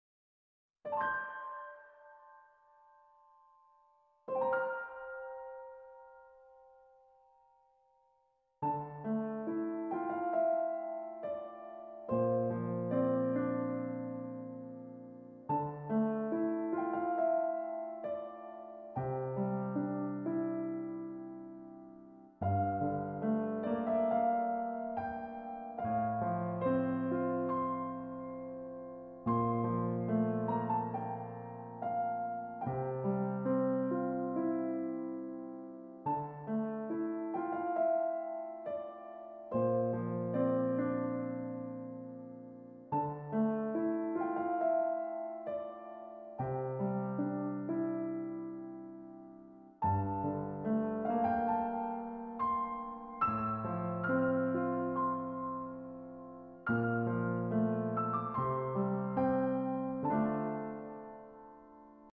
Musik-Relaksasi-Pendek-1-Menit-Luangkan-Waktumu-Sejenak-Untuk-Mendengarkannya.mp3